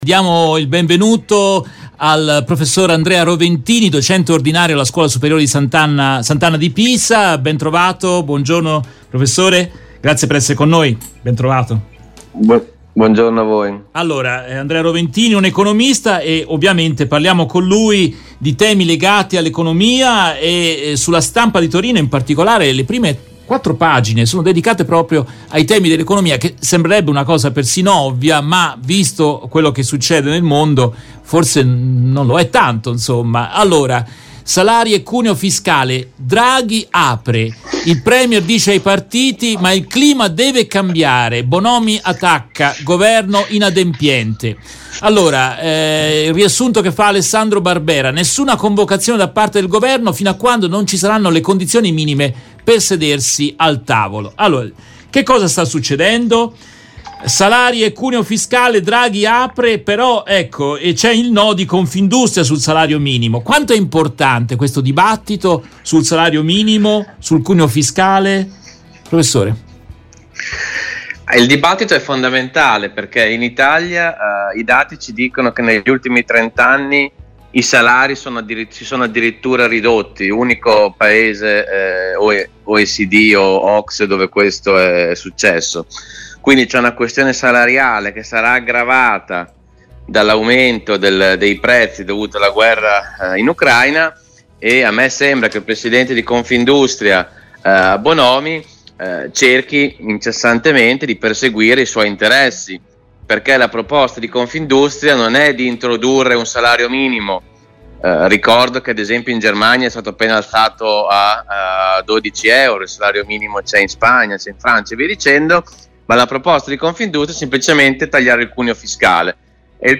In questa intervista tratta dalla diretta RVS del 6 giugno 2022